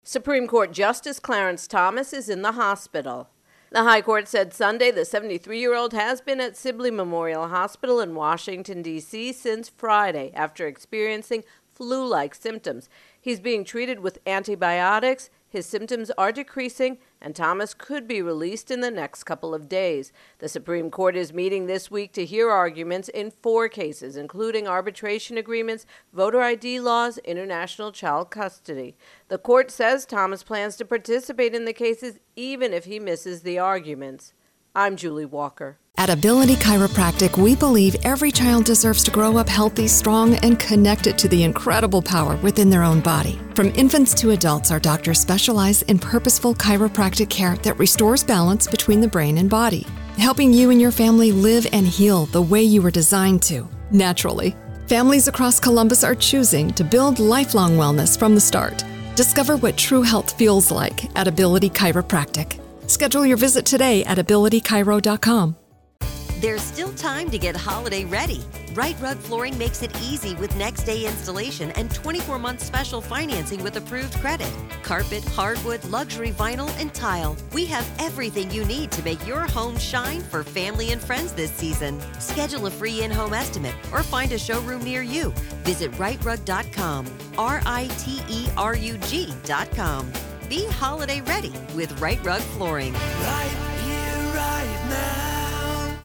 Supreme Court Thomas intro and voicer